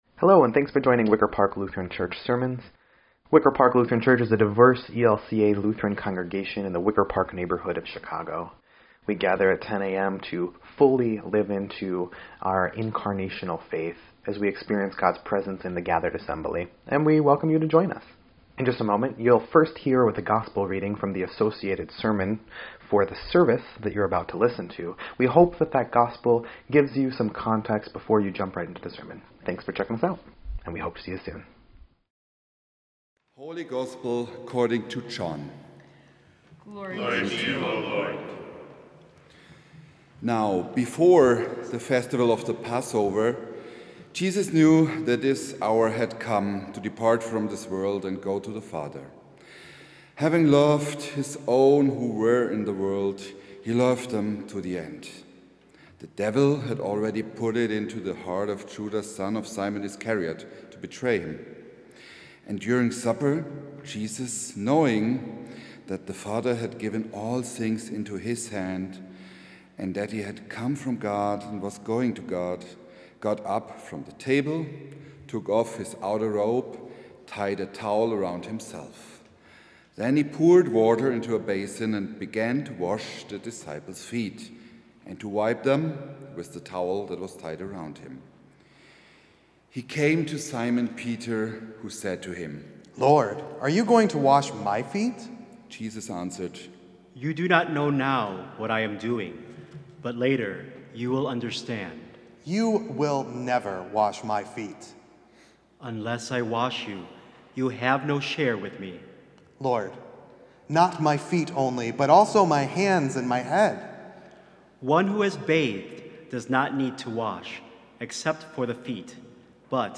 4.17.25-Sermon_EDIT.mp3